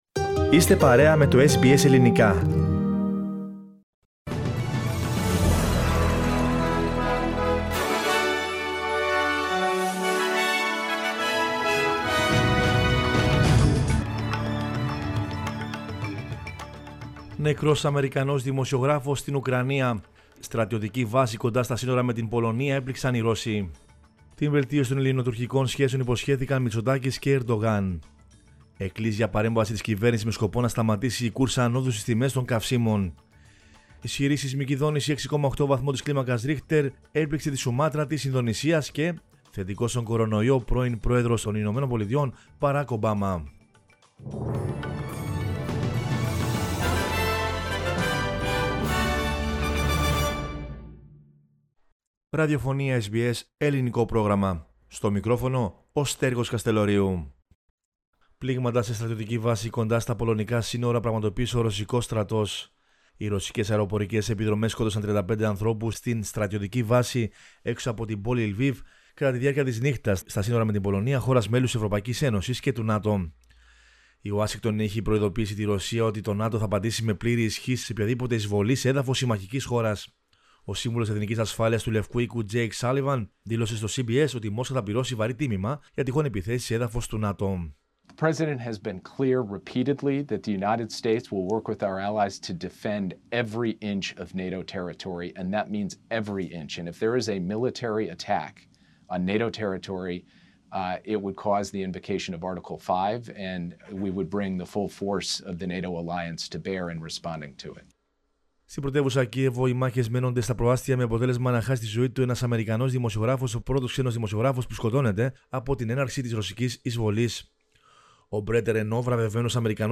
News in Greek from Australia, Greece, Cyprus and the world is the news bulletin of Monday 14 March 2022.